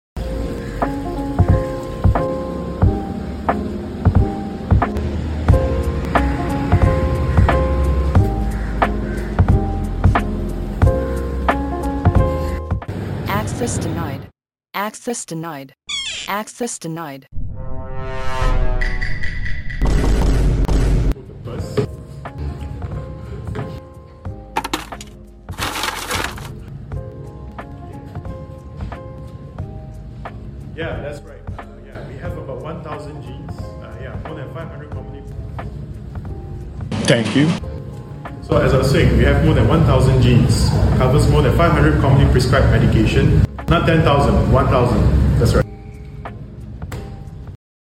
--- 🚨 Disclaimer: This video is a comedy sketch and NOT a representation of the pharmacogenomics test.